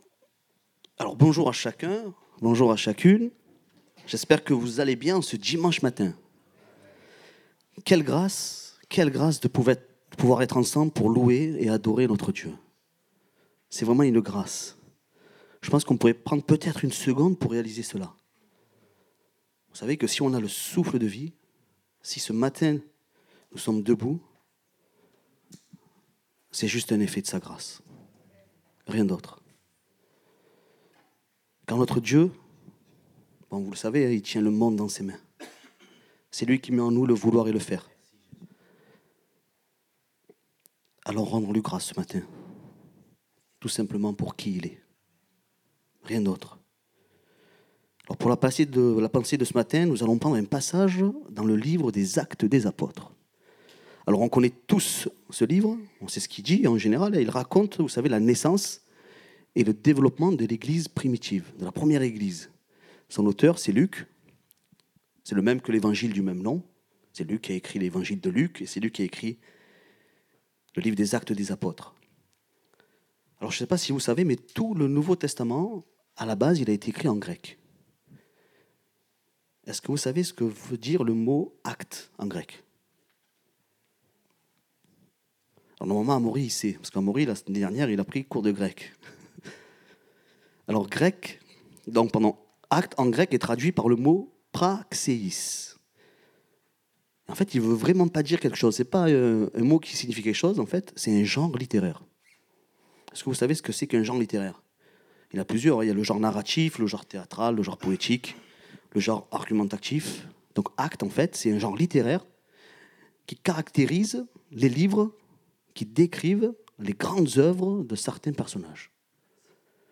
Date : 6 octobre 2024 (Culte Dominical)